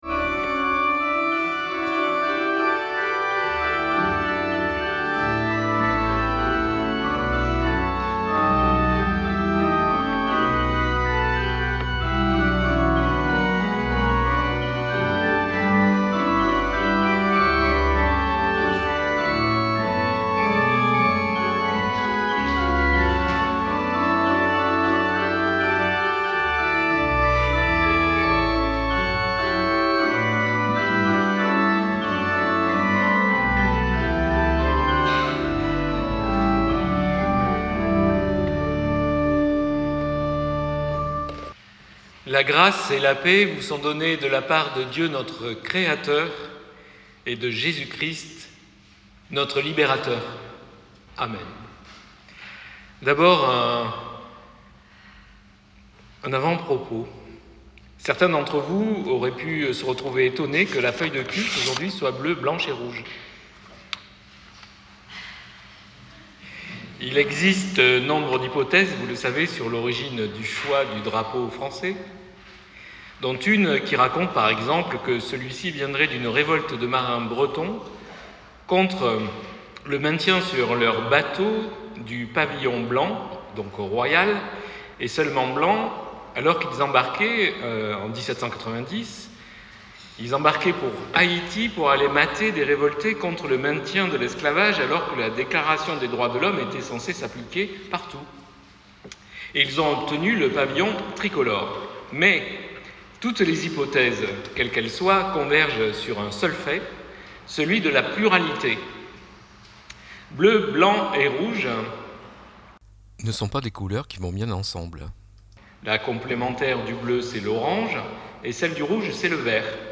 Audio avec extrait du culte (sans les chants de l'assemblée). La prédication débute à 12 minutes 44 ORGUE